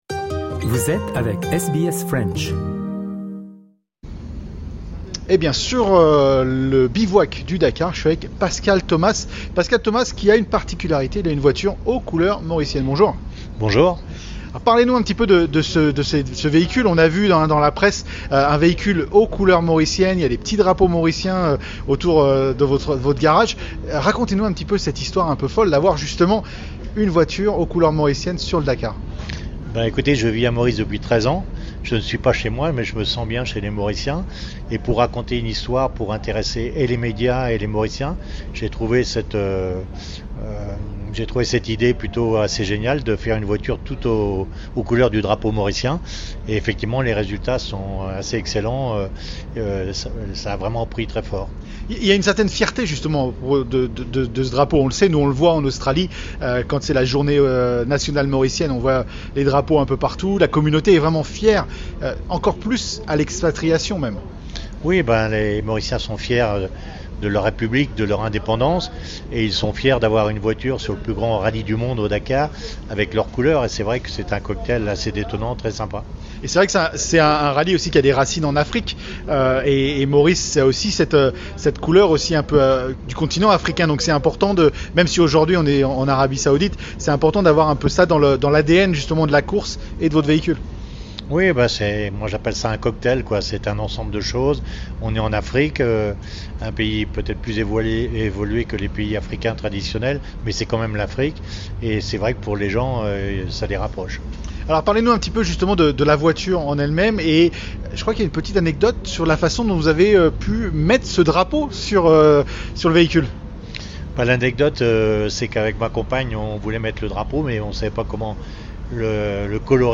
Rencontre avec SBS French à Bisha avant le depart.